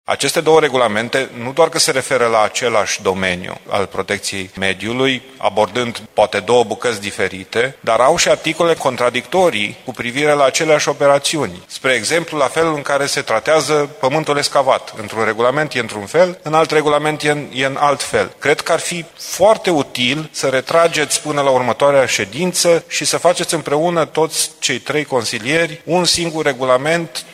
Consilierul PNL, Dan Diaconu.